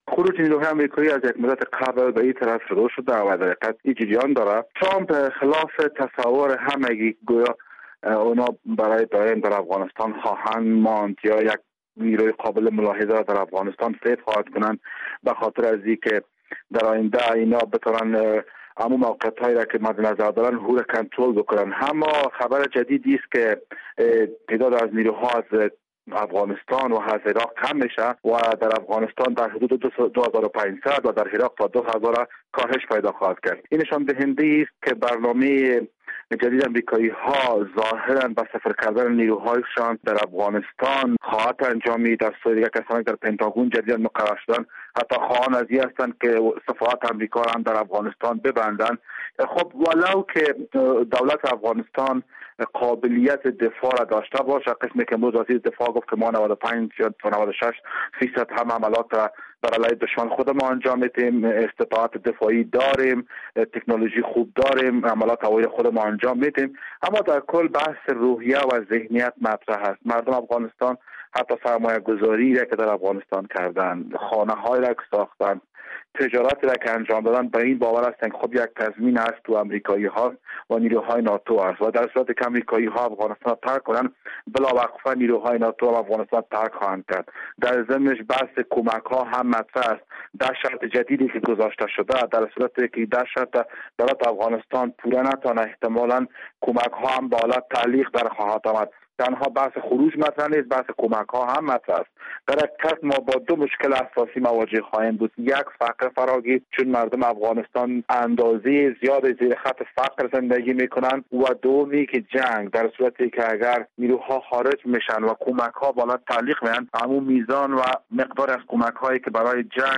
بر اساس این گزارش، پنتاگون همچنین می‌خواهد حدود نیمی از نیروهای آمریکایی را از افغانستان خارج کند و شمار آنها را به دو هزار و ۵۰۰ نفر برساند. گفت‌وگوی